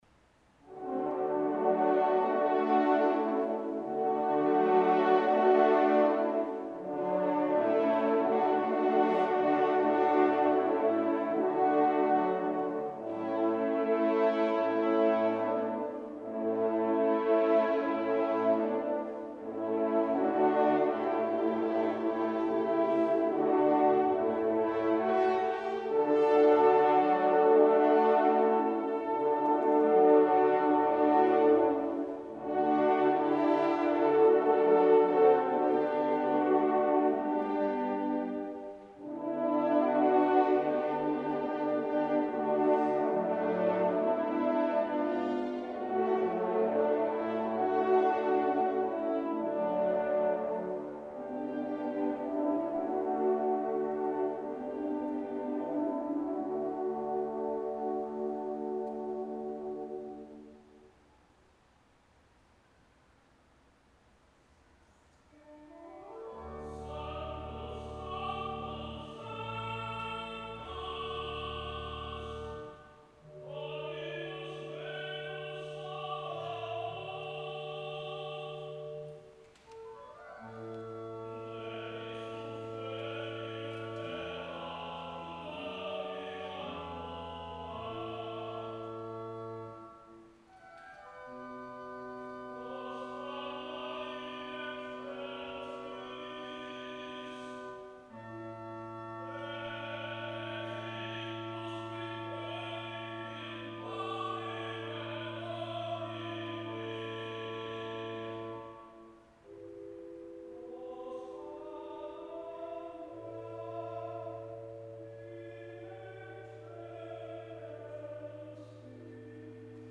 tenor
organ
recorded live at Yale